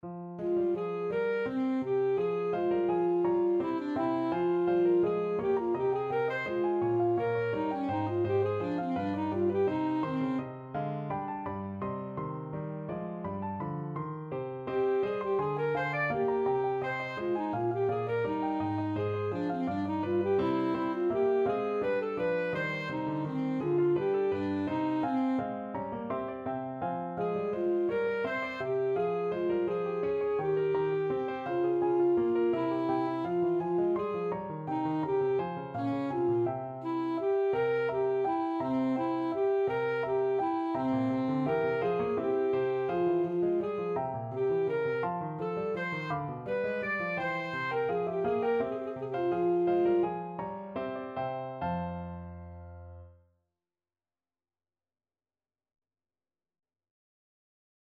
Alto Saxophone version
Alto Saxophone
3/8 (View more 3/8 Music)
Classical (View more Classical Saxophone Music)